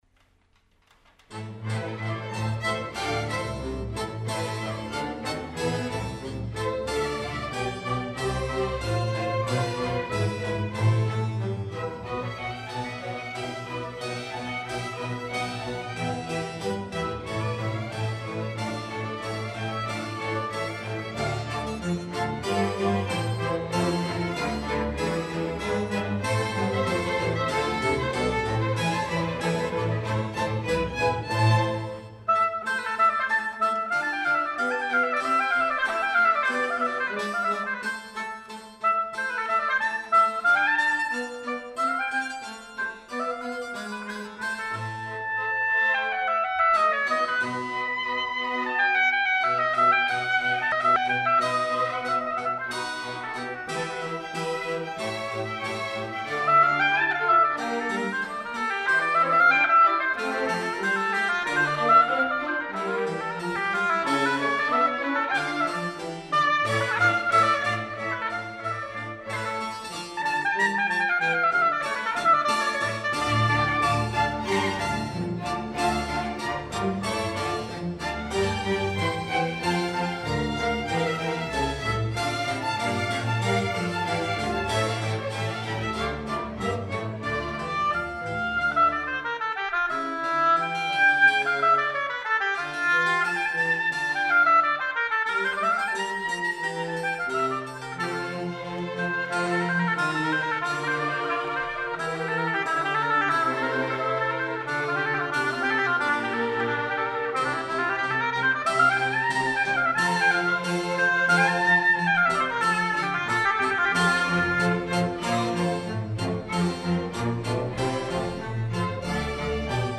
oboe concerto in A minor
Classic
i loved that solo, I presume that was the oboe?